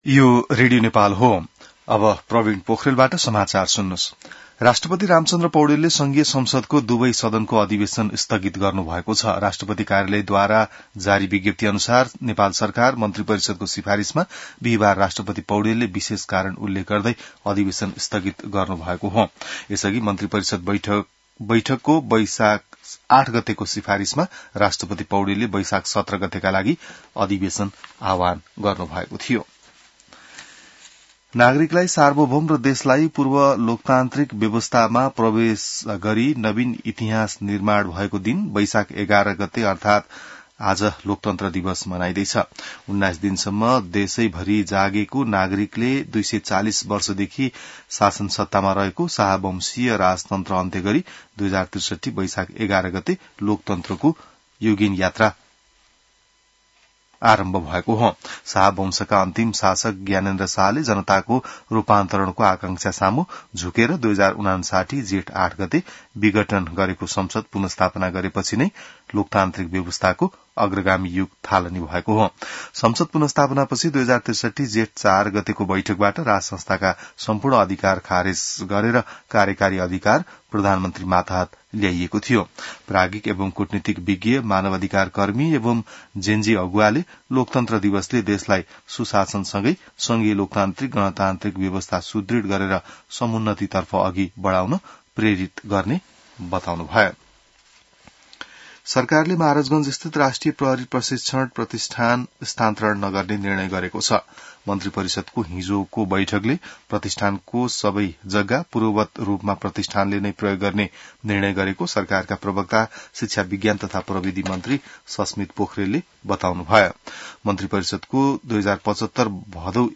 बिहान ६ बजेको नेपाली समाचार : ११ वैशाख , २०८३